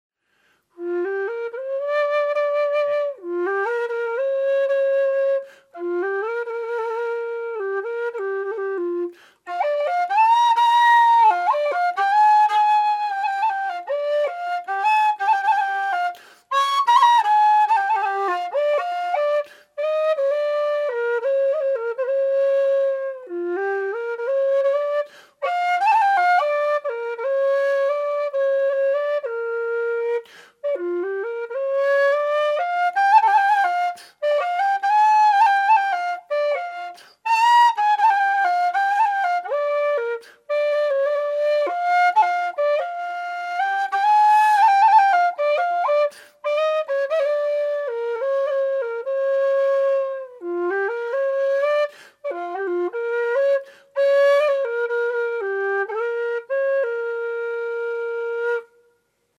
Some sound samples from various Gypsy whistles:
Low G-Gypsy sound sample (bluesy): (:audioplayer
Note: although the scale patterns are given, as intervals between notes in semi-tones, the Gypsy whistles are not tuned in equal temperament, but for a good sounding intonation, especially for the harmonic and double harmonic scales.
G-gypsy-blues2.mp3